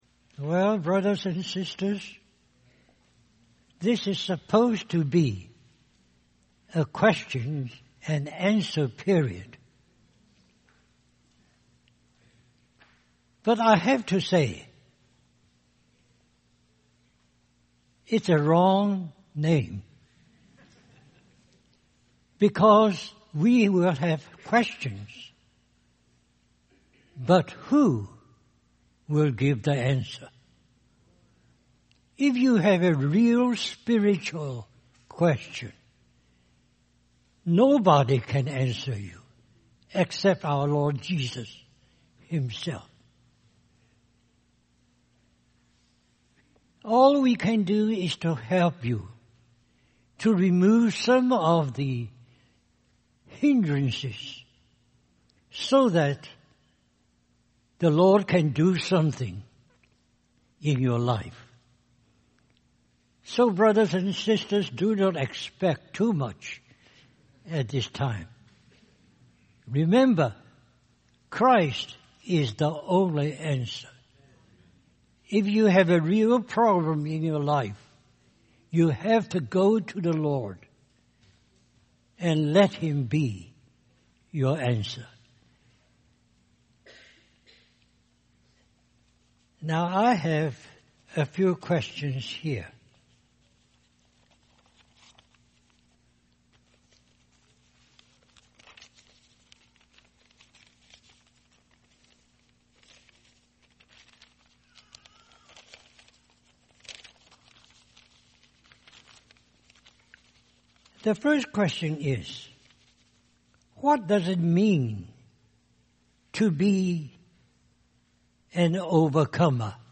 Occupy Till I Come - Questions and Answers
Harvey Cedars Conference